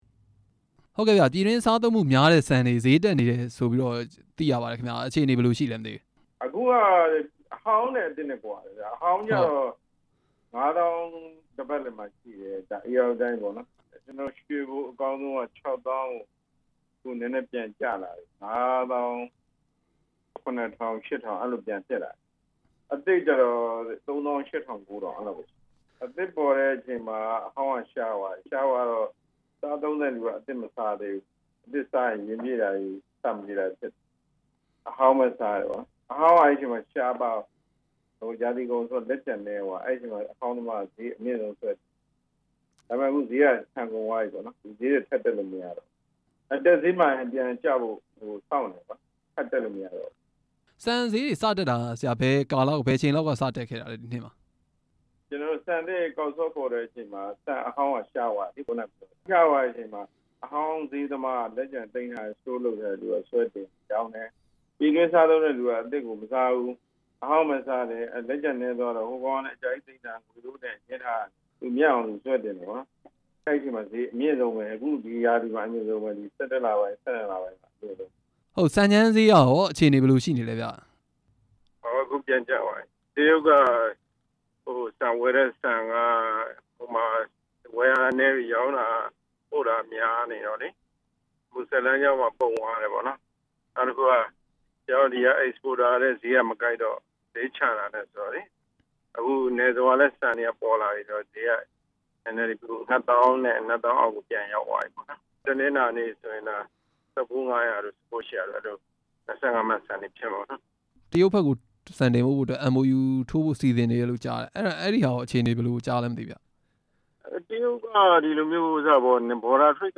ဆန်ဈေး မြင့်တက်နေမှု မေးမြန်းချက်